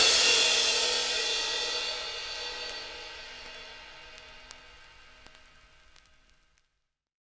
CymST_70s_3_MPC60V.wav